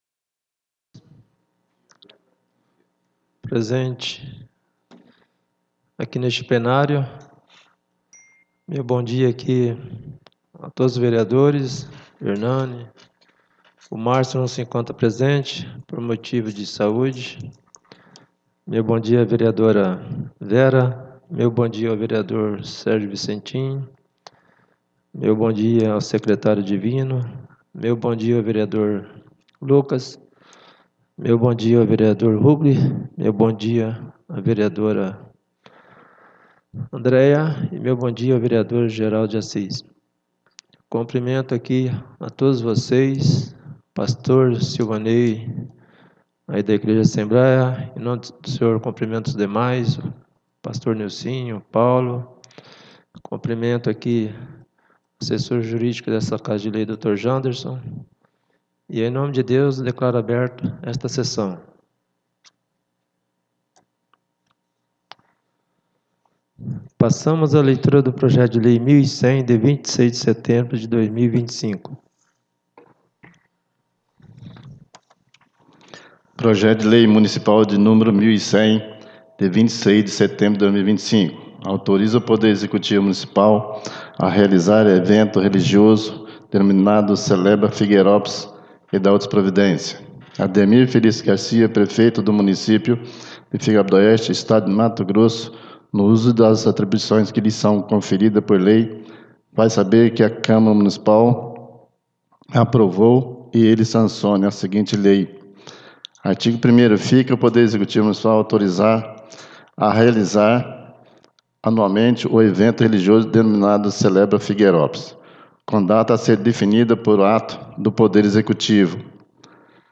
13° SESSÃO ORDINARIA DIA 29 DE SETEMBRO DE 2025